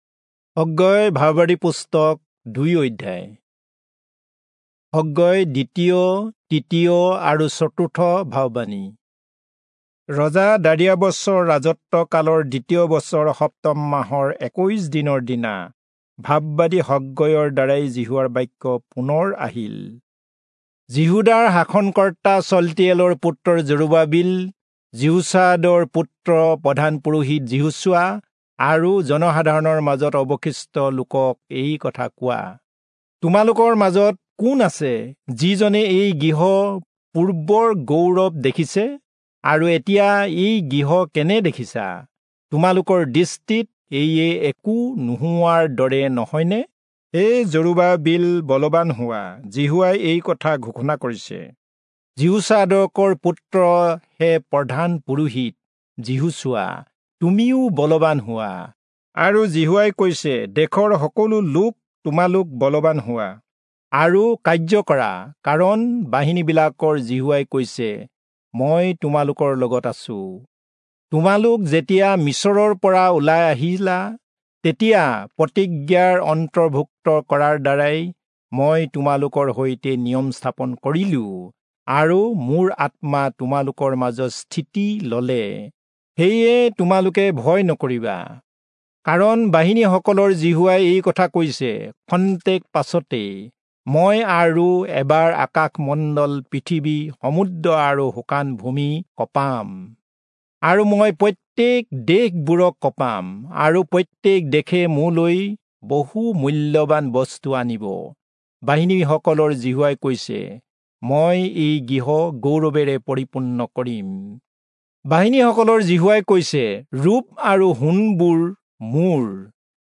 Assamese Audio Bible - Haggai 2 in Litv bible version